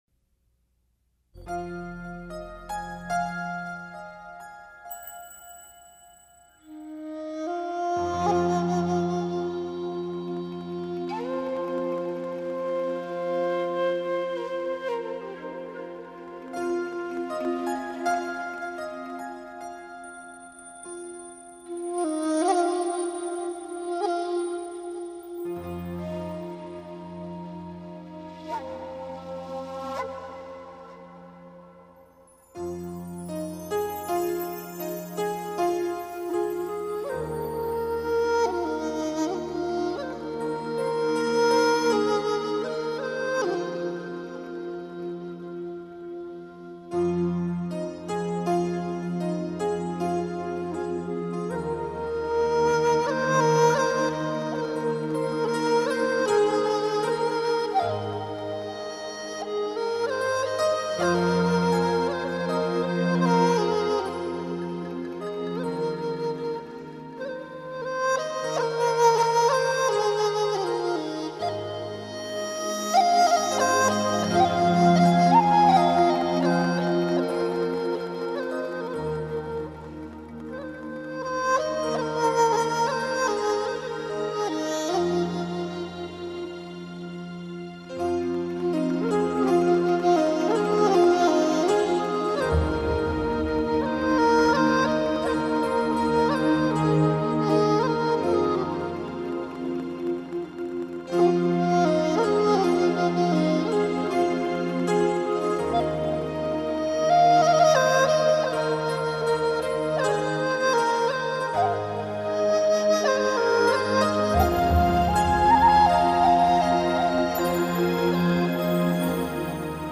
由日本的尺八、印度笛、西洋长笛、中国竹笛四位音乐大师，每人出一段旋律，现场录音。